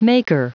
Prononciation du mot maker en anglais (fichier audio)
Prononciation du mot : maker